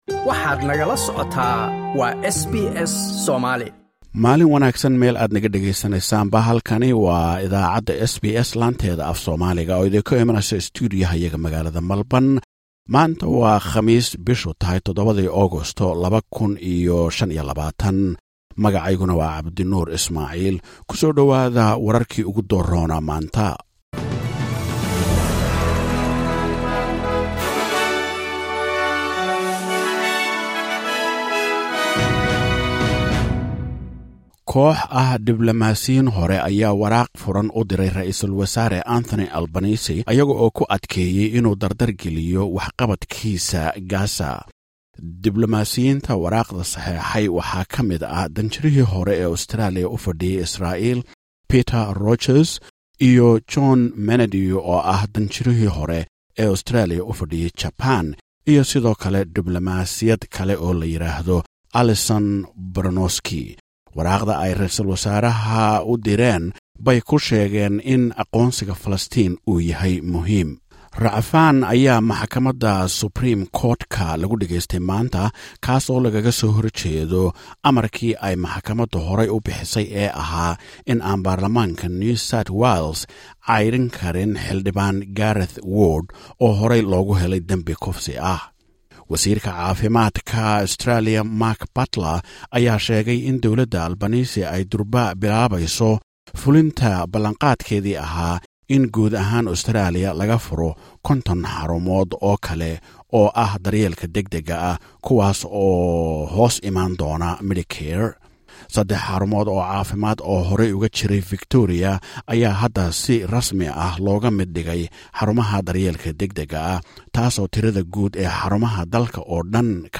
Warkii ugu dambeeyey ee Australia iyo caalamka maanta oo khamiis ah.